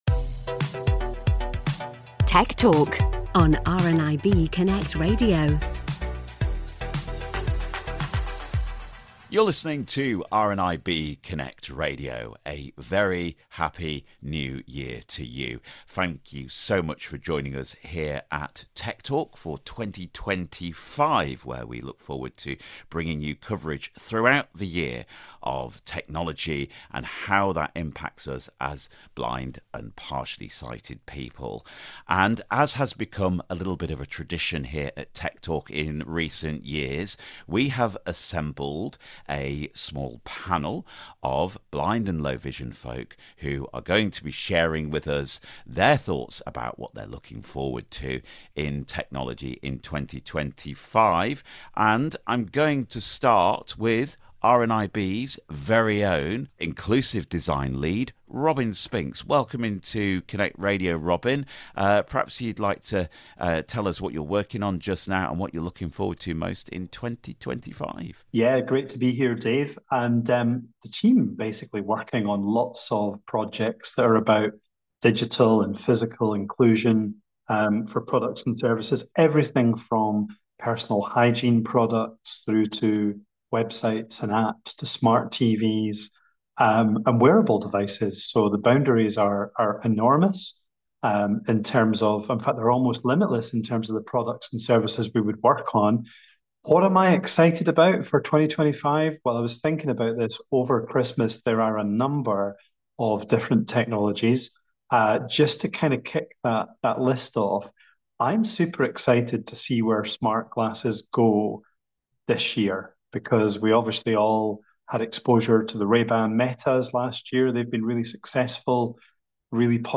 This time on Tech Talk, we're thinking about what the year ahead may bring to the world of accessible technology and beyond. We're joined by some special guests to hear their thoughts on everything from AI to social media.